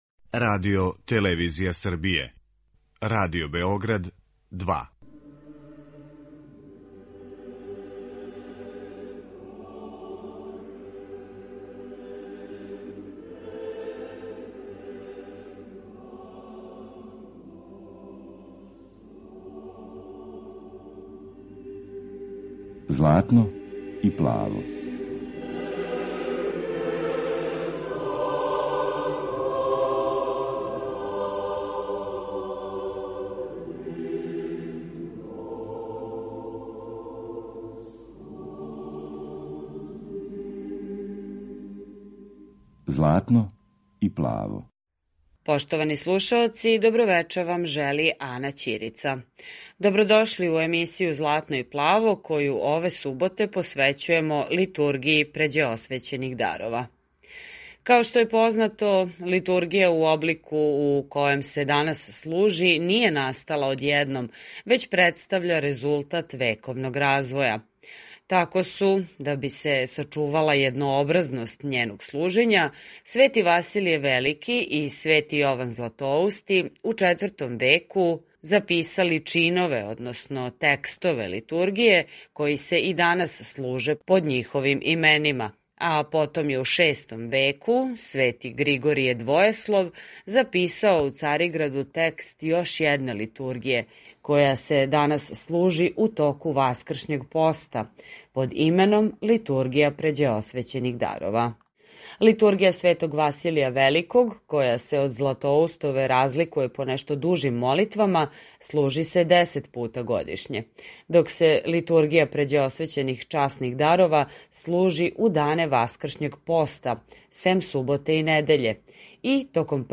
Eмисија православне духовне музике